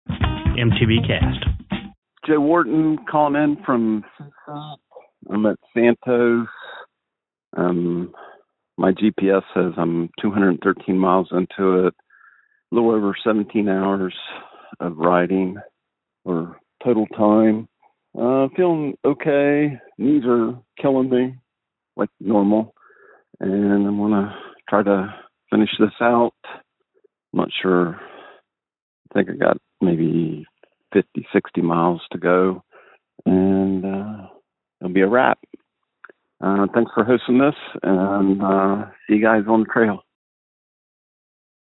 called in from Santos!